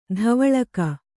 ♪ dhavaḷaka